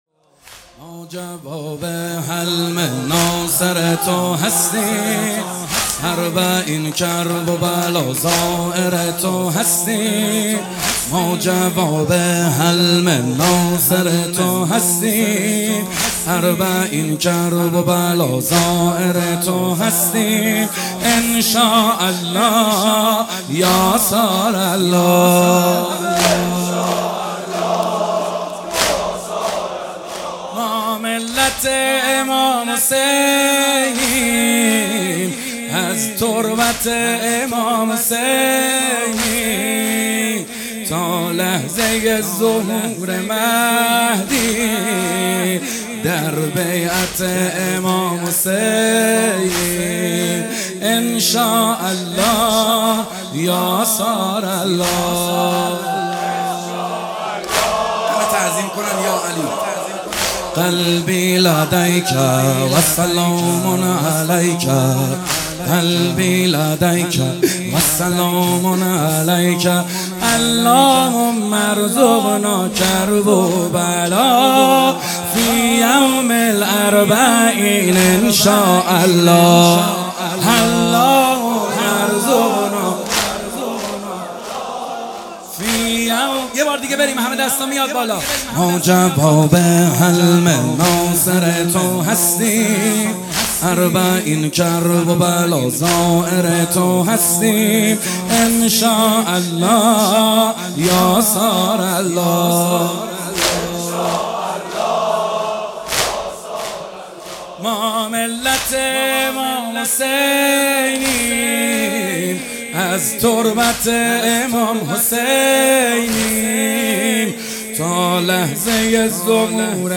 مداحی محمدحسین حدادیان | هیئت عشاق الرضا (ع) تهران |محرم 1399 | پلان 3